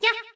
toadette_yah.ogg